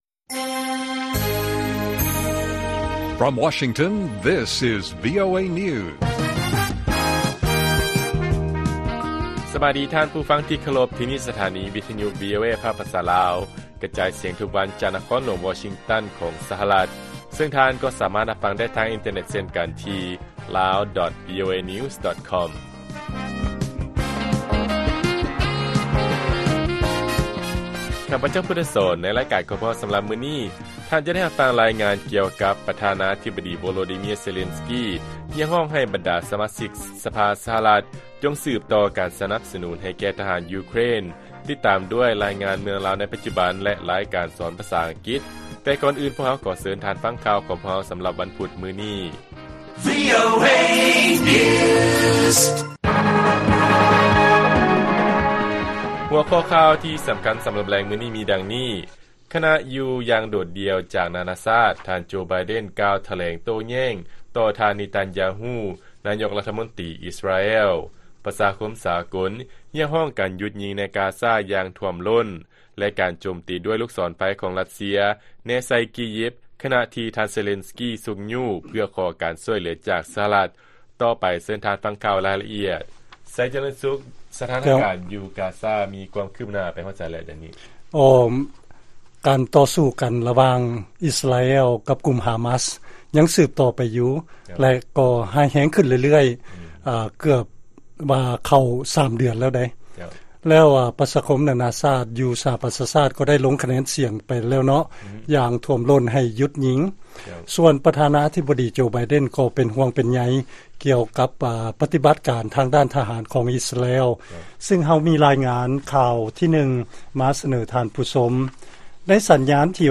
ວີໂອເອພາກພາສາລາວ ກະຈາຍສຽງທຸກໆວັນ, ຫົວຂໍ້ຂ່າວສໍາຄັນໃນມື້ນີ້: 1.ທ່ານໄບເດັນ ໄດ້ກ່າວຖະແຫລງໂຕ້ແຍ້ງ ຕໍ່ ທ່ານເນທັນຢາຮູ ນາຍົກລັດຖະມົນຕີອິສຣາແອລ, 2. ປະຊາຄົມສາກົນ ຮຽກຮ້ອງການຢຸດຍິງ ໃນ ກາຊາ ຢ່າງຖ້ວມລົ້ນ, ແລະ 3. ການໂຈມຕີດ້ວຍລູກສອນໄຟຂອງຣັດເຊຍ ແນໃສ່ ກີຢິບ.